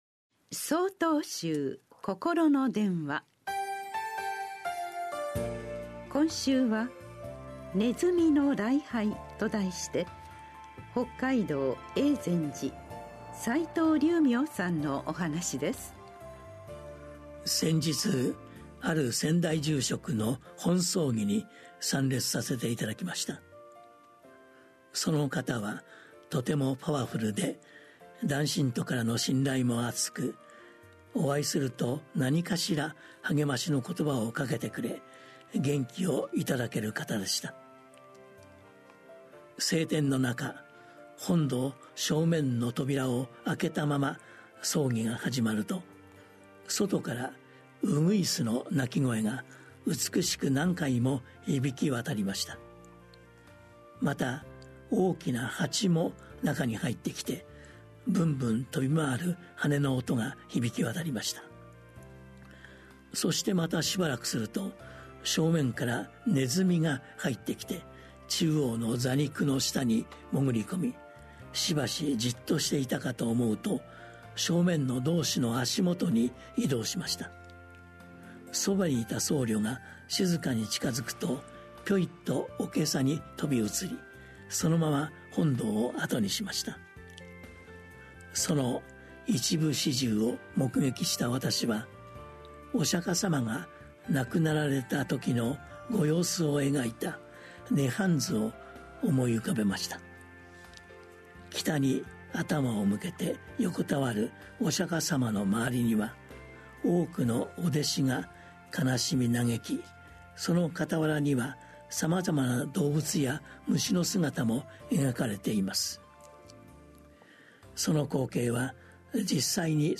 心の電話（テレホン法話）９/23公開『鼠の礼拝』 | 曹洞宗 曹洞禅ネット SOTOZEN-NET 公式ページ